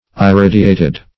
iridiated - definition of iridiated - synonyms, pronunciation, spelling from Free Dictionary Search Result for " iridiated" : The Collaborative International Dictionary of English v.0.48: Iridiated \I*rid"i*a`ted\, a. Iridescent.